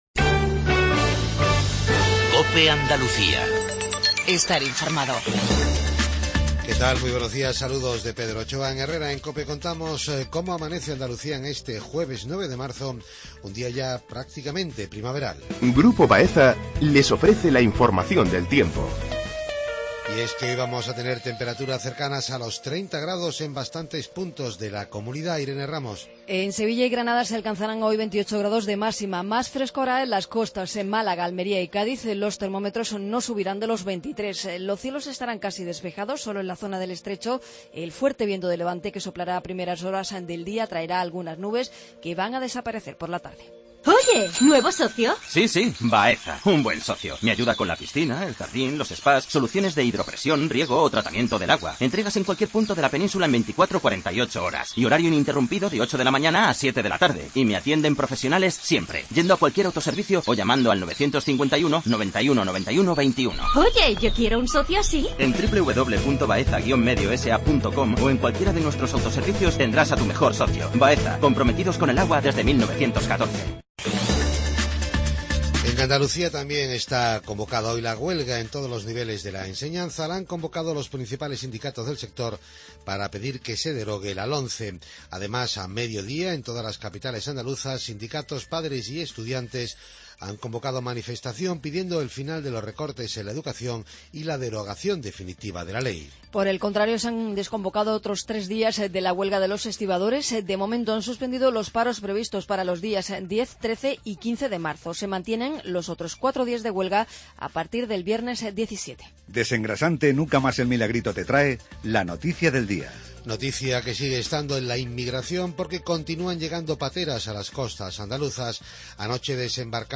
INFORMATIVO REGIONAL/LOCAL MATINAL 7:50